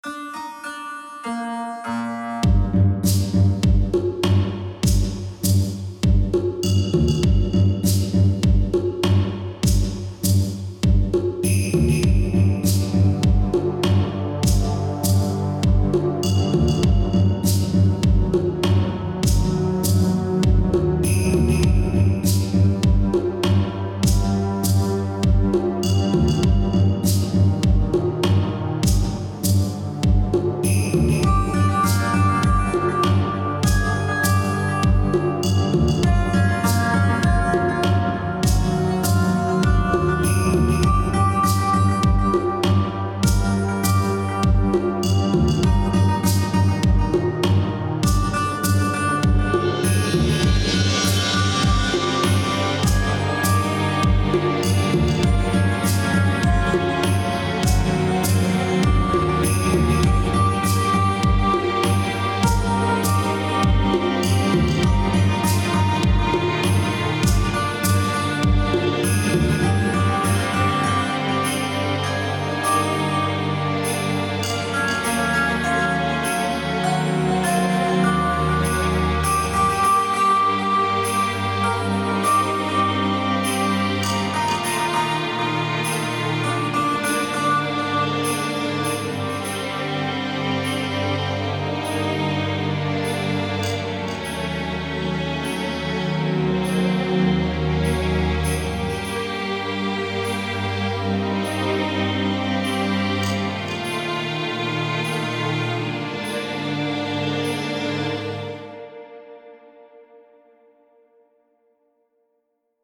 Kind of sounds like it could be used for a spy game.